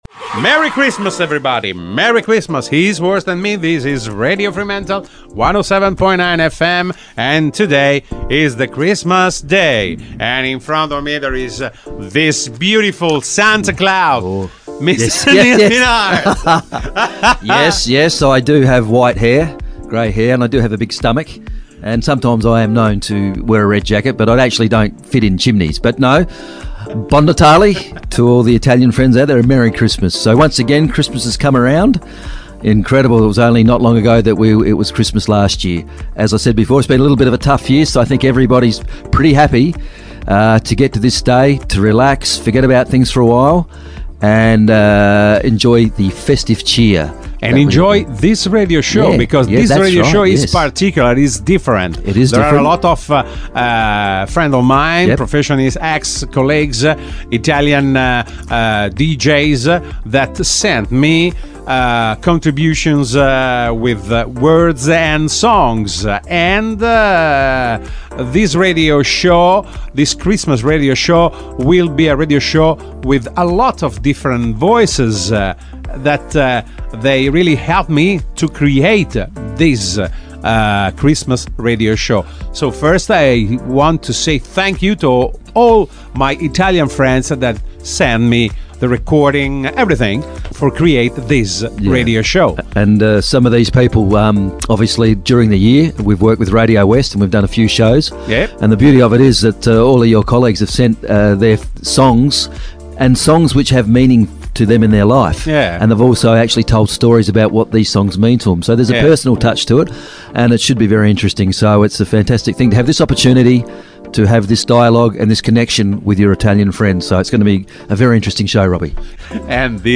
Le voci di Radio West in onda su Radio Fremantle